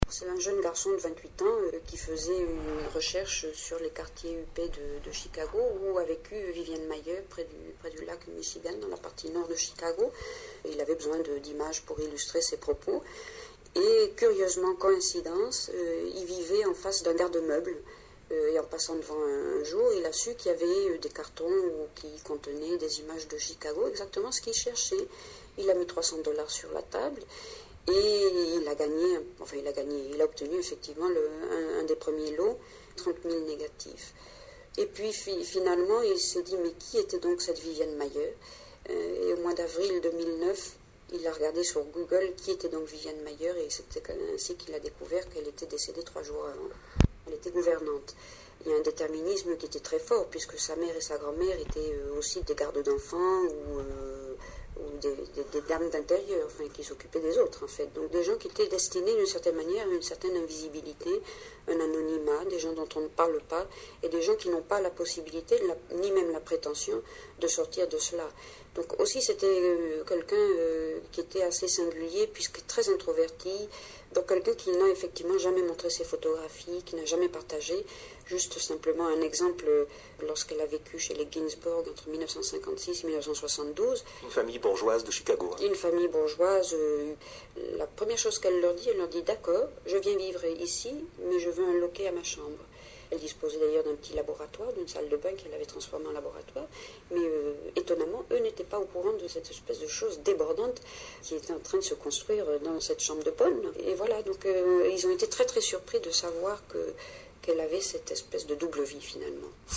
Voici ce que disait l’autre jour à la radio la commissaire de cette exposition.